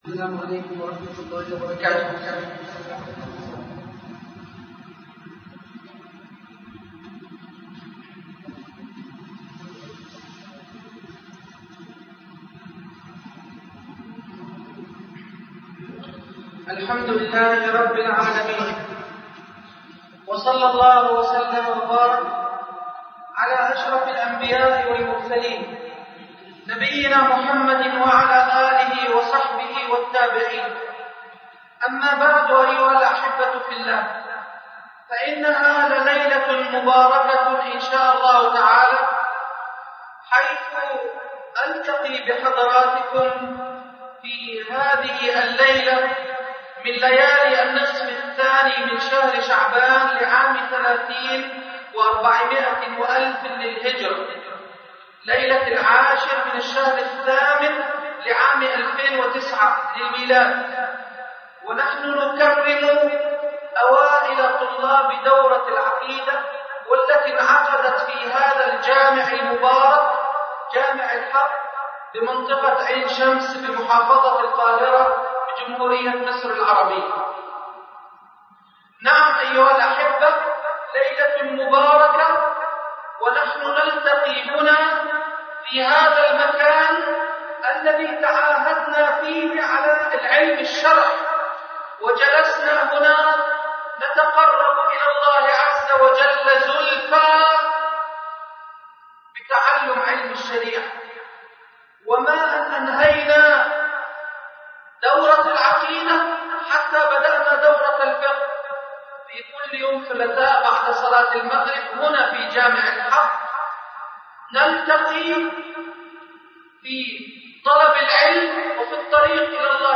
الدرس الاخير - حفل تكريم اوائل دورة العقيدة